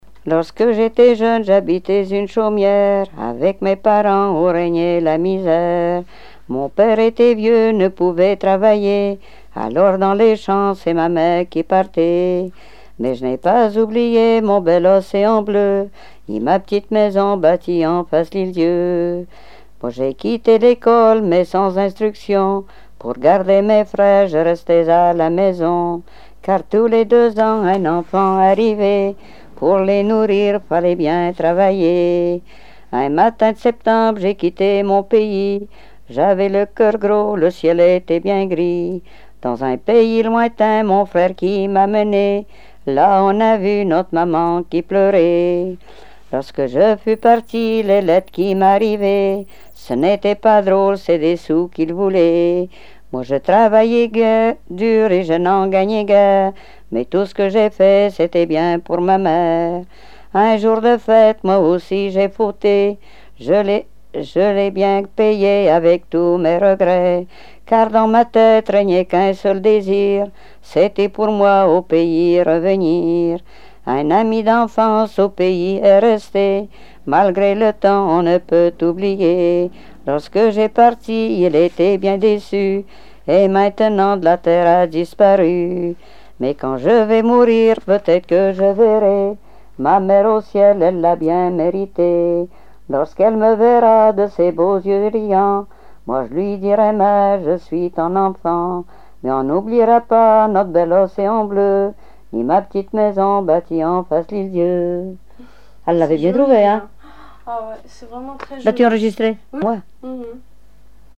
Genre strophique
Répertoire de chansons traditionnelles et populaires
Pièce musicale inédite